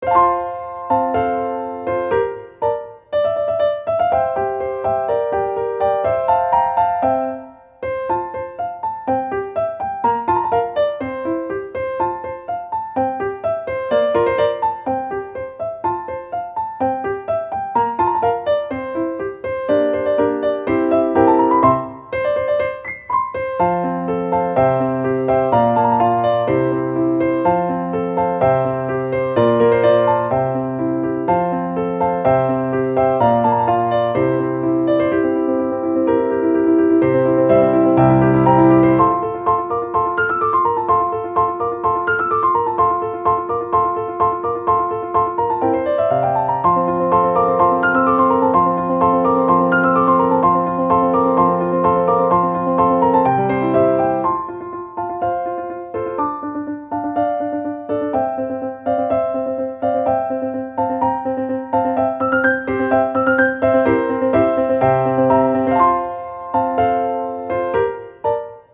ogg(L) - 元気 楽しい かわいい
小気味良い旋律を弾む音符に乗せて。